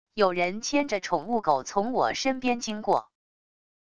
有人牵着宠物狗从我身边经过wav音频